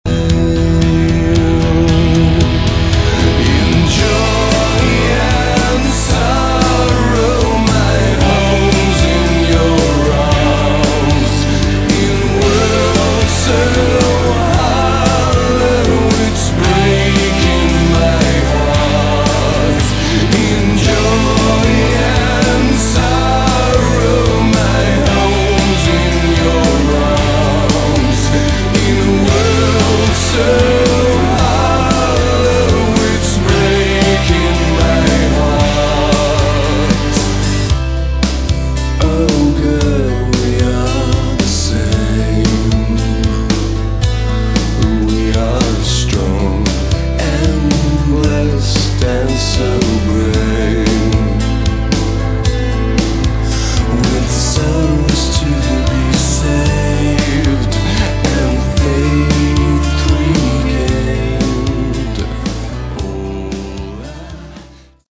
Category: Rock